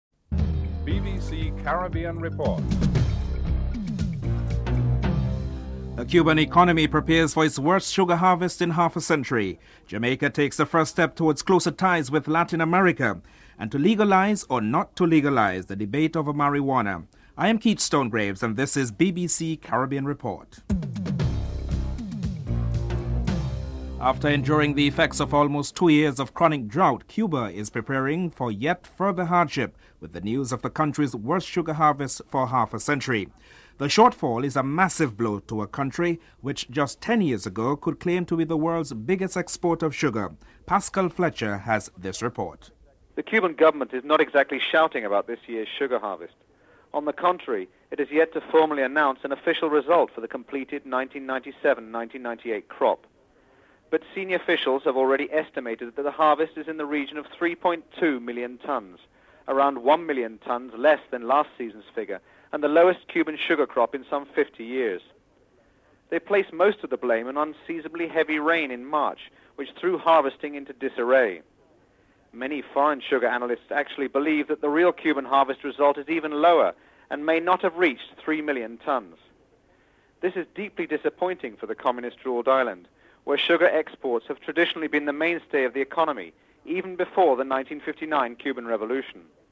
1. Headlines (00:00-00:22)
4. Jamaica takes a first step towards closer ties with Latin America. President Carlos Menem of Argentina and Prime Minister P.J. Patterson are interviewed.